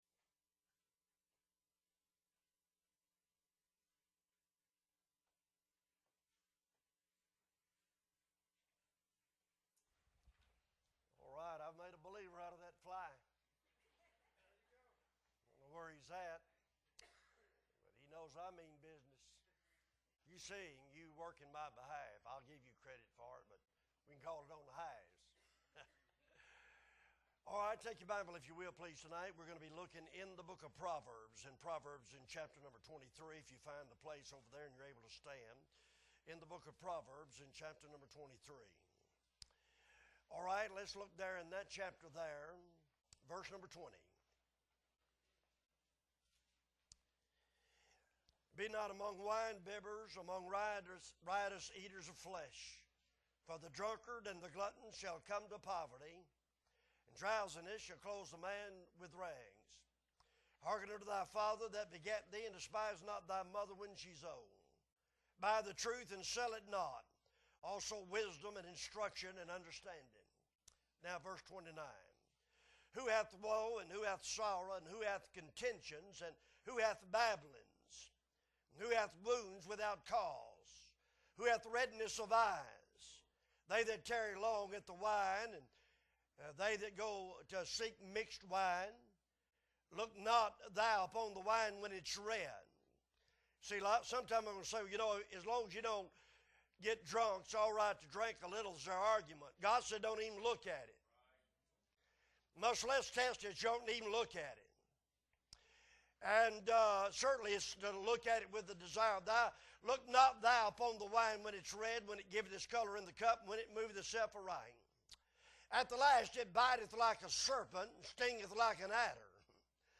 September 25, 2022 Sunday Evening Service - Appleby Baptist Church